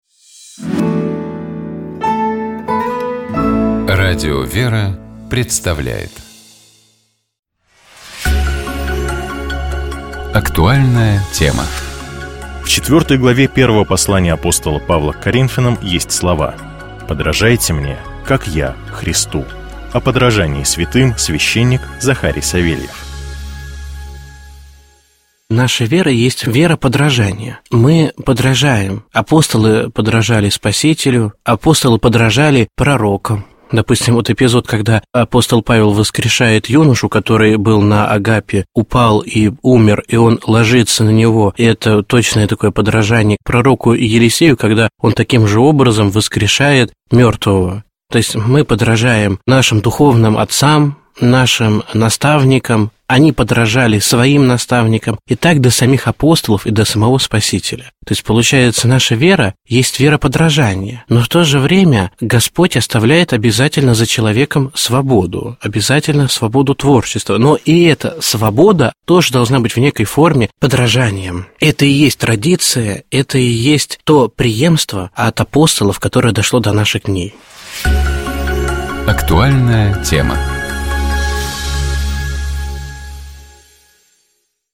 У нас в студии